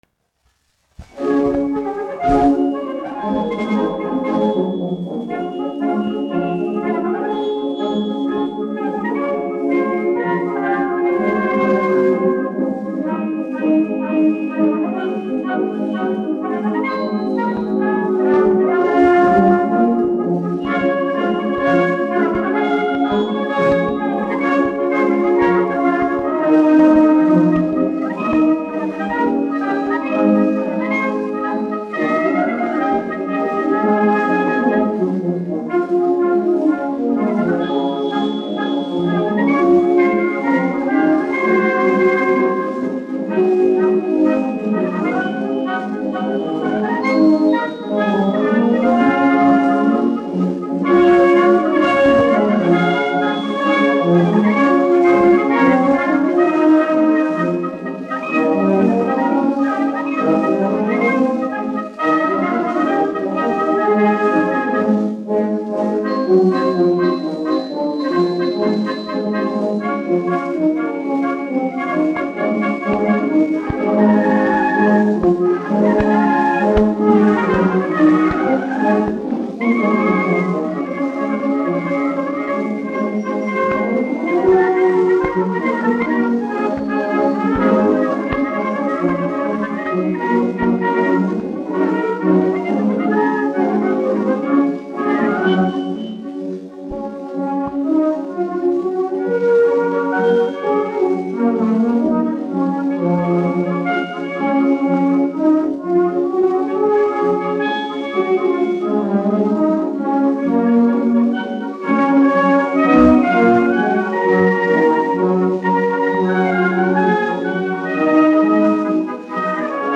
6. Rīgas kājnieku pulka orķestris, izpildītājs
1 skpl. : analogs, 78 apgr/min, mono ; 25 cm
Marši
Pūtēju orķestra mūzika
Skaņuplate